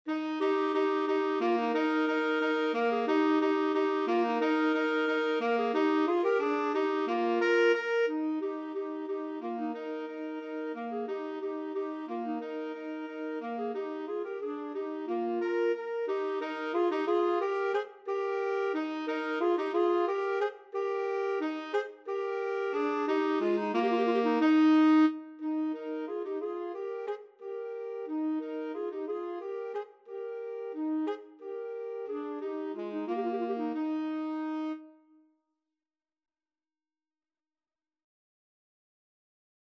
Free Sheet music for Clarinet-Tenor Saxophone
=180 Vivace (View more music marked Vivace)
Eb major (Sounding Pitch) (View more Eb major Music for Clarinet-Tenor Saxophone )
4/4 (View more 4/4 Music)
Clarinet-Tenor Saxophone  (View more Easy Clarinet-Tenor Saxophone Music)
Classical (View more Classical Clarinet-Tenor Saxophone Music)